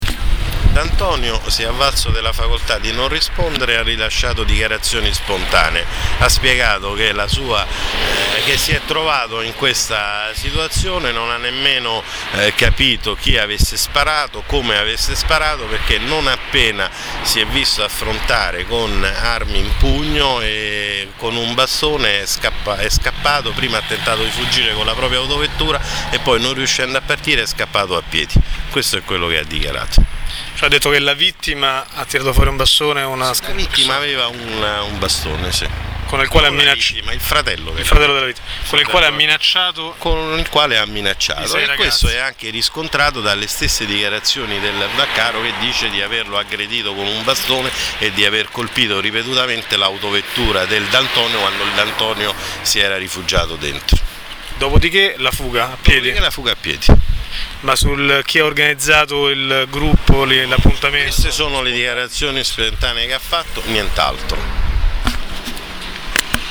Per i dettagli sulle dichiarazioni ascoltare le interviste agli avvocati difensori rilasciate subito dopo l’interrogatorio: